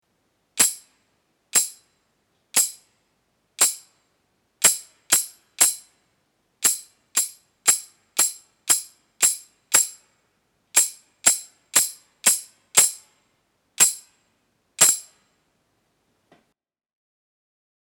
Ditado rítmico